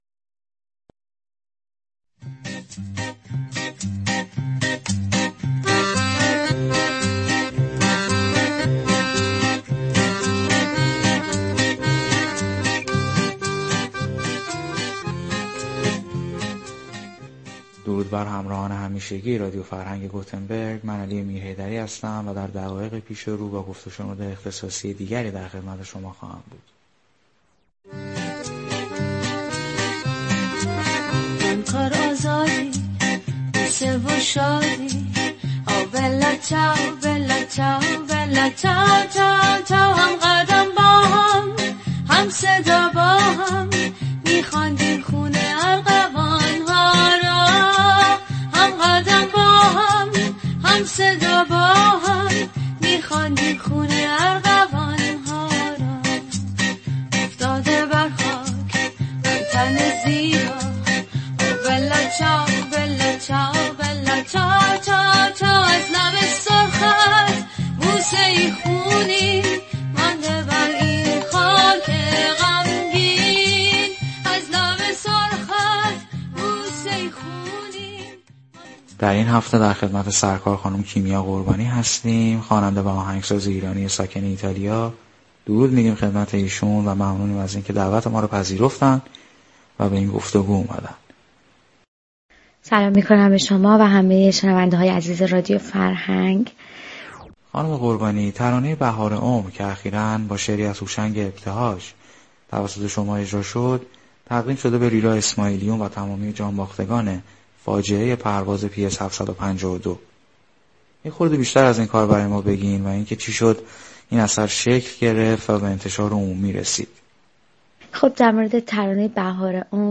گفت و شنود اختصاصی رادیو فرهنگ